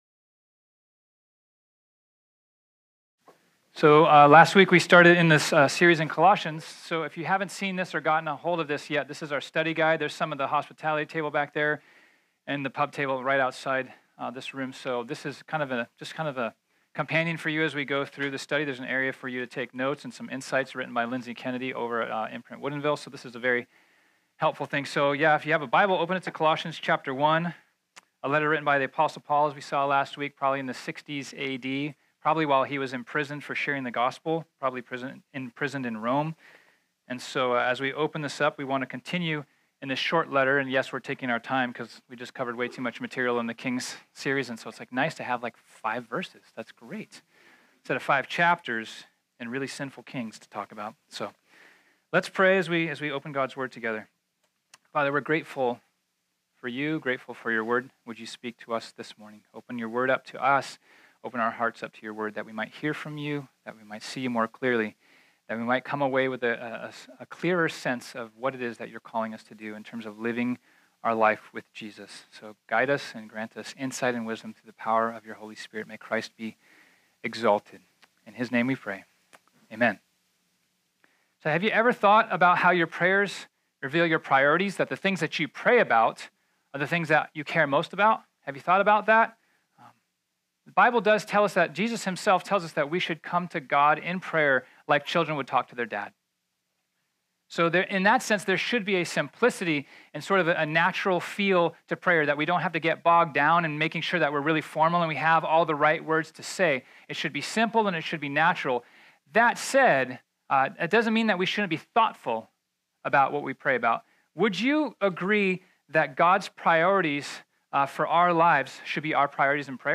This sermon was originally preached on Sunday, September 16, 2018.